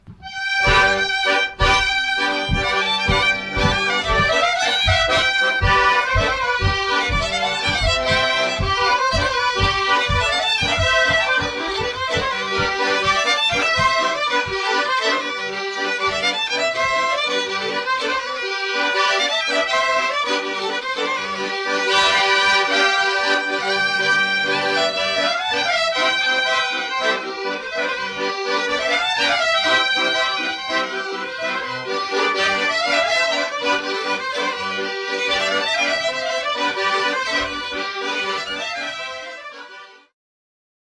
Mazurek (Nieznamierowice, Radomskie 1981)
Badania terenowe
skrzypce
harmonia 3-rzędowa, 120-basowa